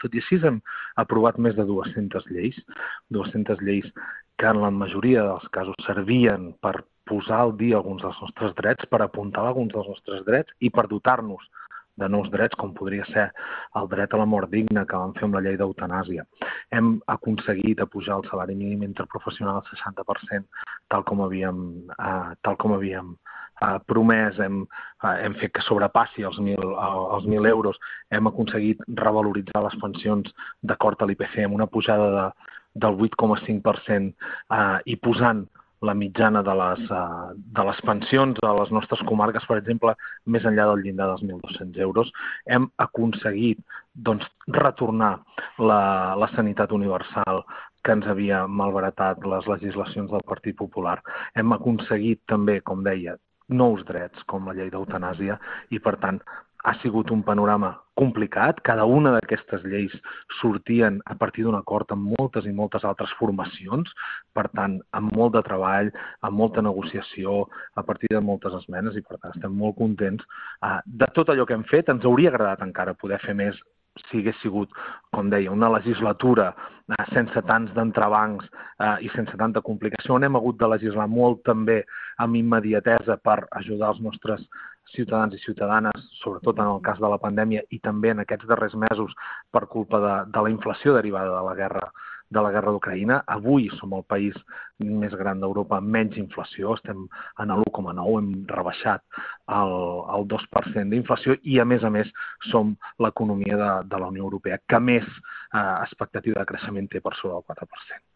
Parlem amb el cap de llista per Girona pel Partit Socialista de Catalunya per les eleccions generals d’enguany, Marc Lamuà, per tractar alguns detalls de la seva candidatura.